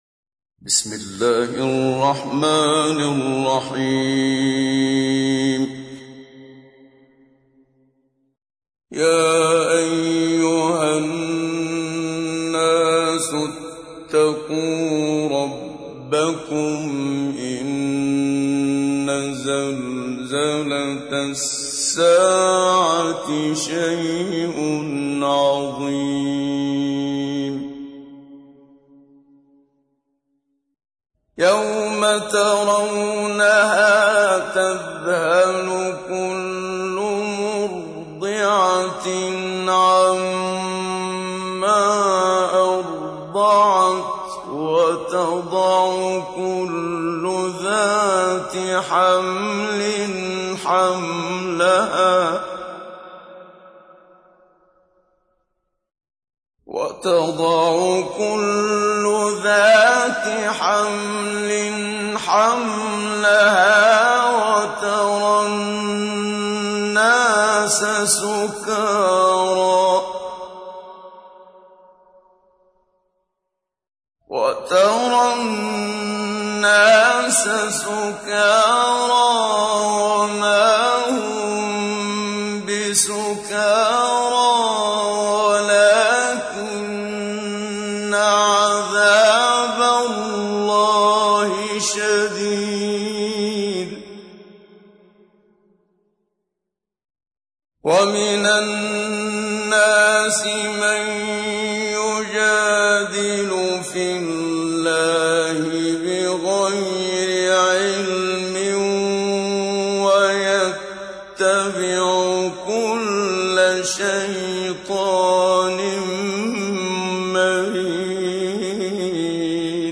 تحميل : 22. سورة الحج / القارئ محمد صديق المنشاوي / القرآن الكريم / موقع يا حسين